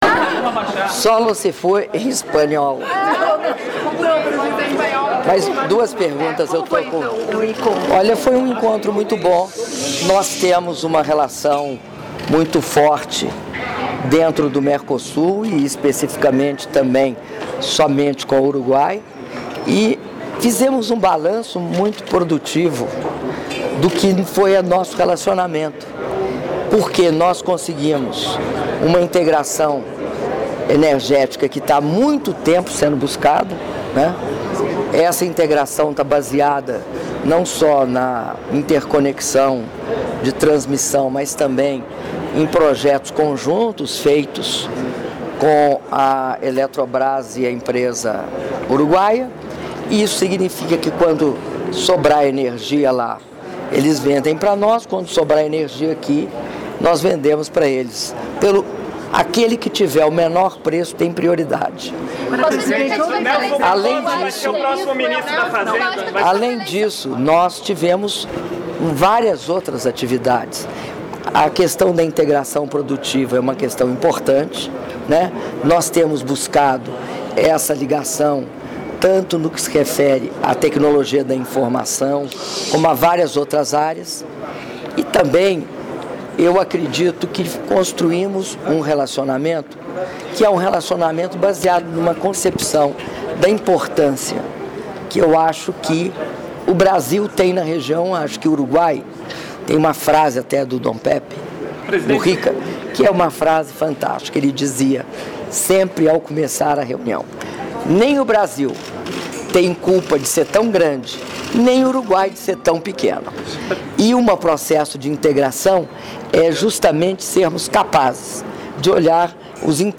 Áudio da entrevista concedida pela Presidenta da República, Dilma Rousseff após visita do Presidente do Uruguai, José Mujica, no Palácio do Planalto (03min11s)